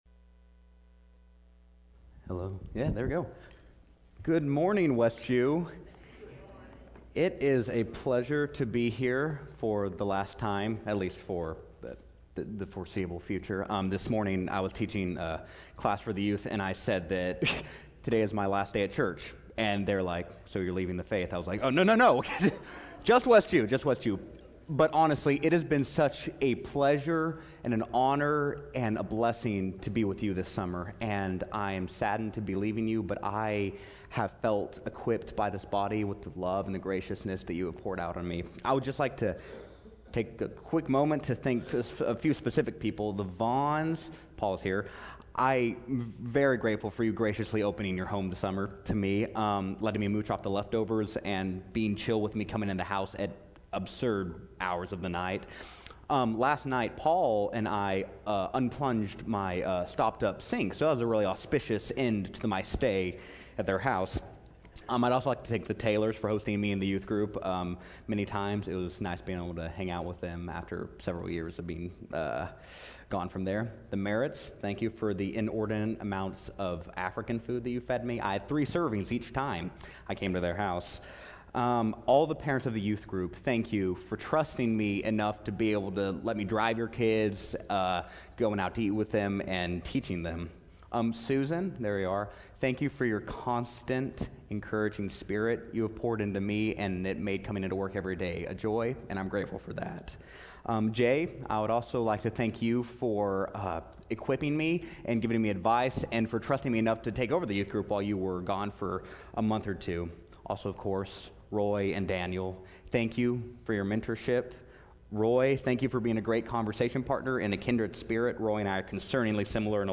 Weekly Sermon Audio “Hear Now